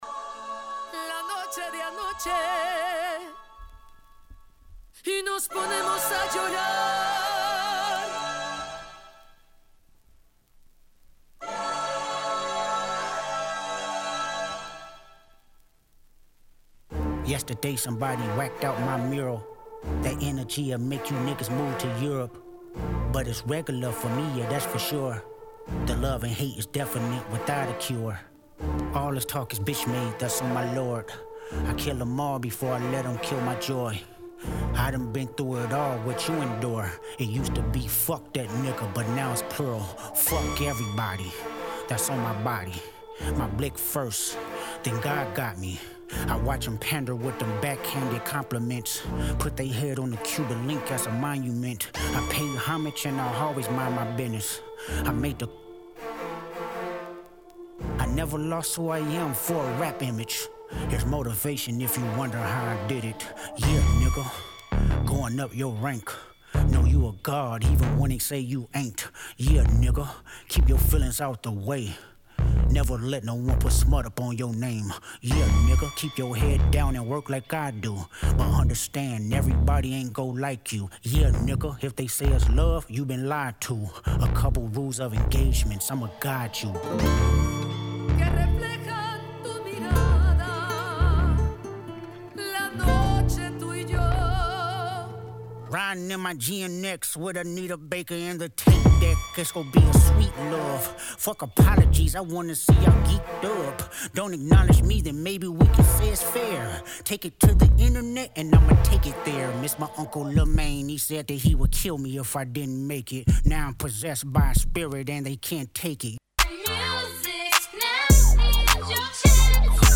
Hiphop-Breakbeat